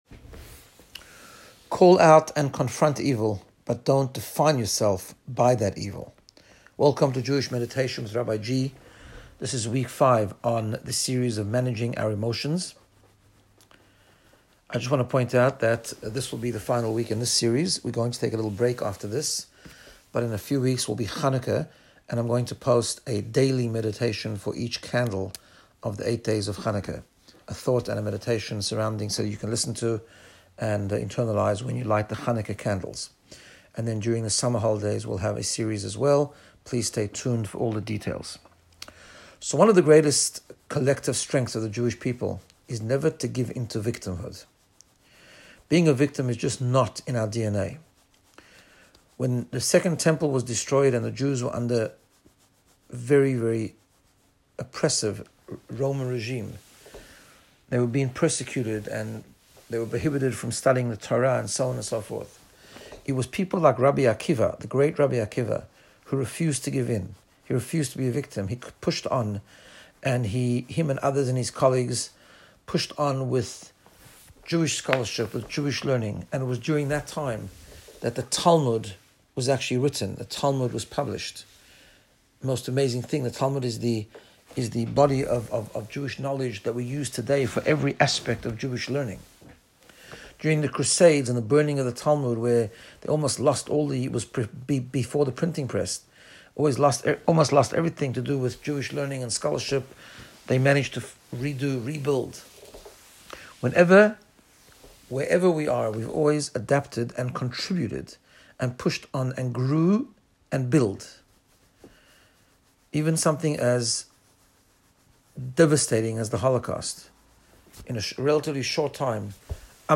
Guided Meditation to get you in the space of Personal Growth and Wellbeing.Managing our thoughts and emotions Part 5In this episode we explore the feeling of victimhood.
Meditation-toldos.m4a